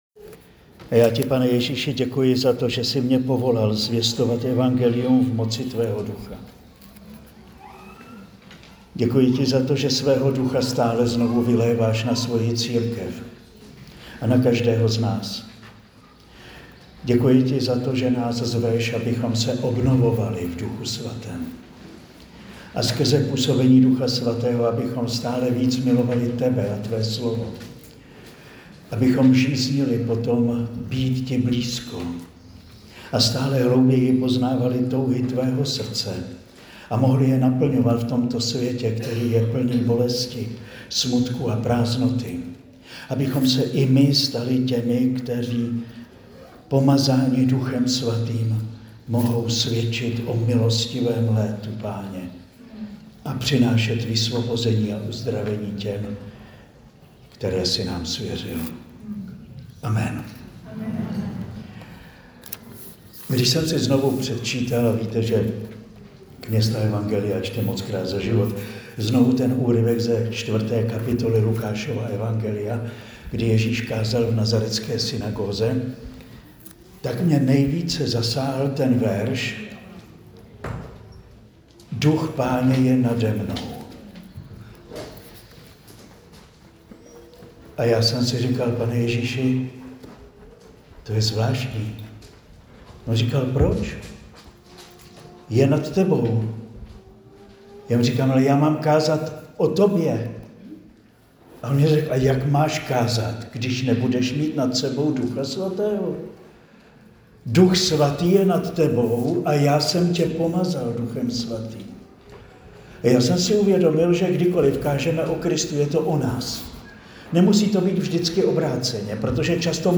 Promluva zazněla na duchovní obnově v Hájku u Prahy dne 1. 5. 2025